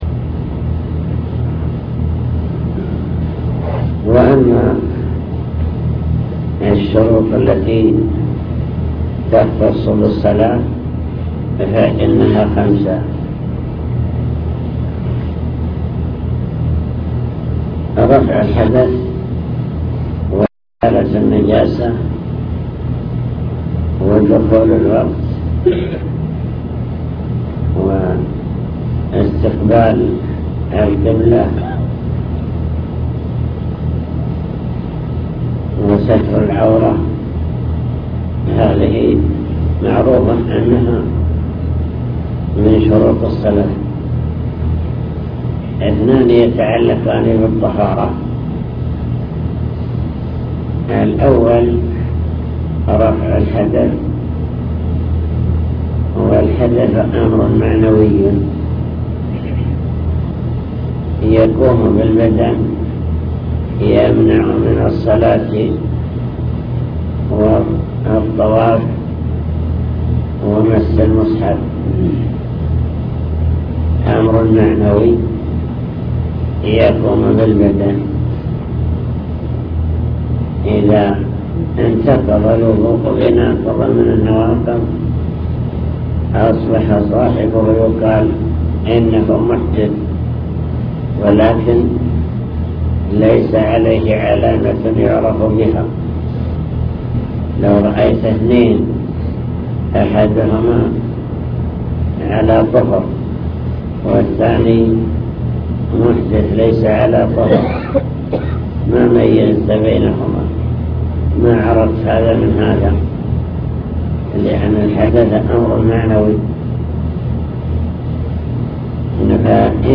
المكتبة الصوتية  تسجيلات - محاضرات ودروس  درس الفجر